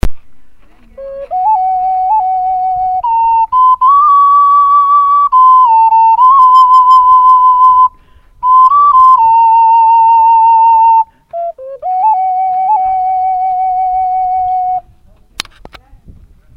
Concert ocarina, Quebrada de Cafayate.MP3